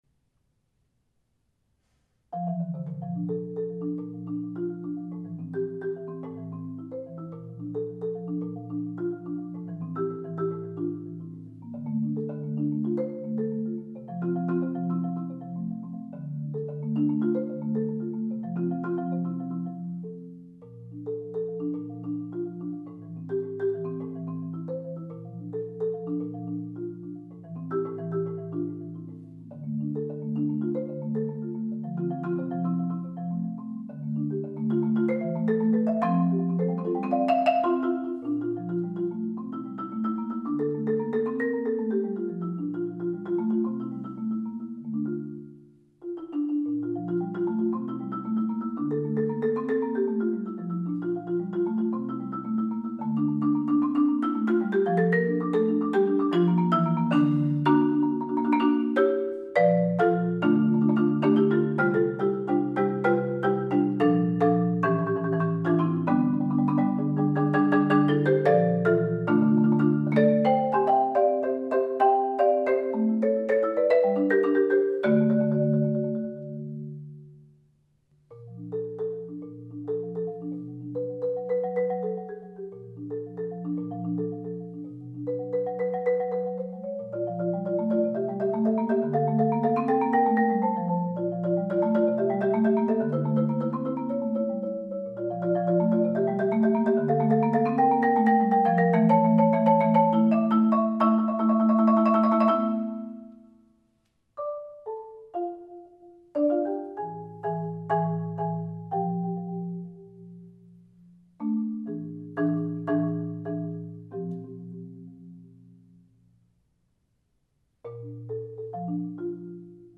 Voicing: Marimba Solo